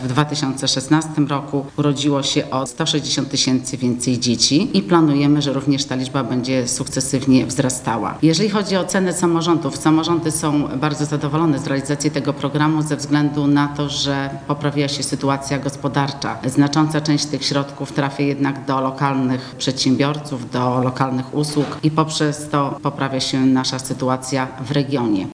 Wśród zalet programu senator wymienia również zwiększenie dzietności w rodzinach i poprawę sytuacji gospodarczej w regionach.